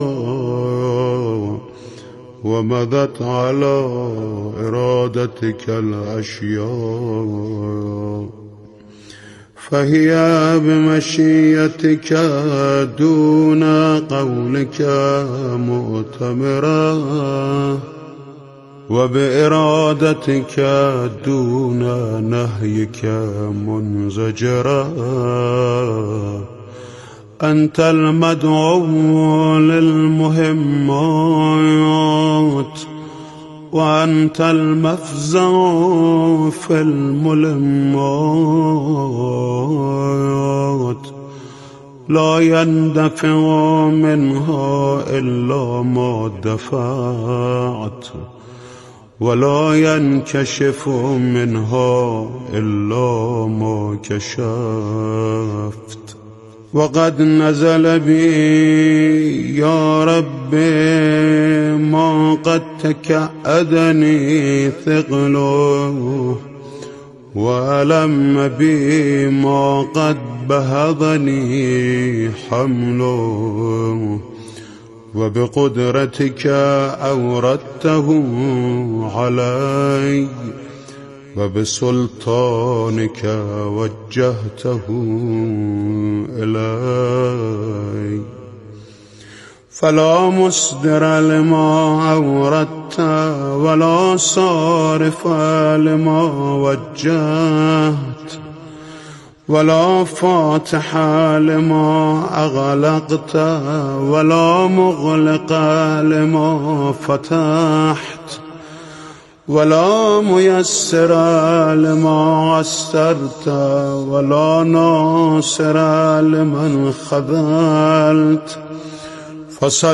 گفتگو درباره اینکه ایا می توان اقتصاد ایران را بدون نفت اداره کرد؟ این گفتگو از رادیو تهران پخش می شود هر روز ساعت بین ساعت ۱۲:۱۰ تا ۱۲:۴۵ با برنامه گفتاورد بانگاهی علمی و اندیشه ای در خدمت شما هستم.